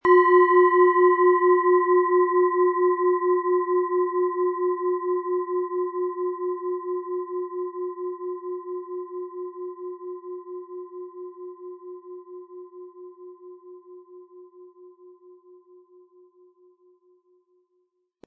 Planetenton 1
Von Hand getriebene Klangschale mit dem Planetenklang Wasser aus einer kleinen traditionellen Manufaktur.
MaterialBronze